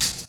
Wu-RZA-Hat 18.WAV